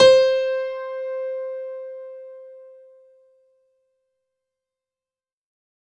C4  DANCE -L.wav